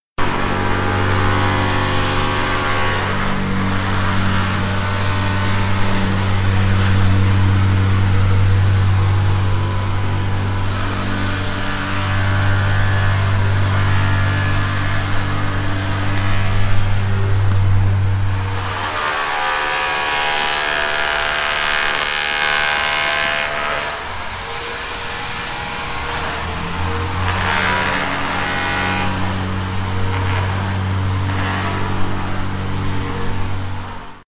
Рингтон Звук реконструкции здания
Звуки на звонок